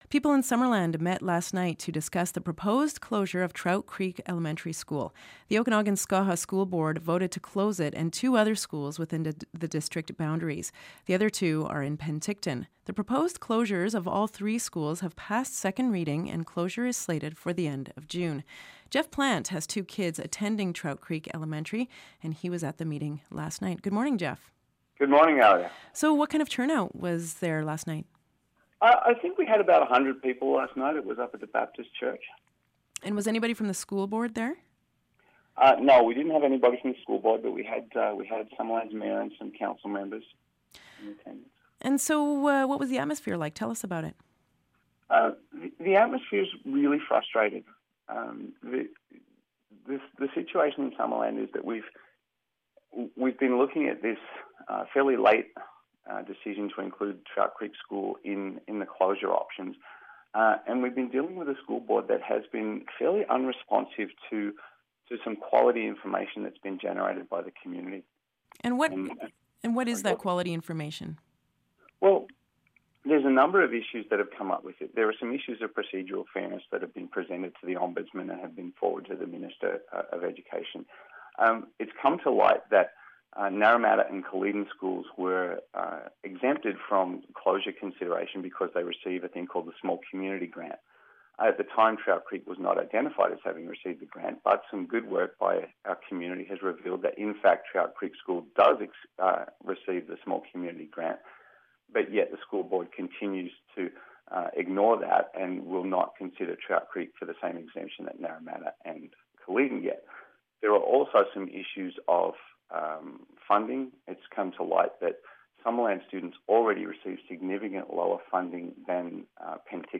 Can people in Summerland stop the closure of Trout Creek Elementary school? One parent shares his thoughts.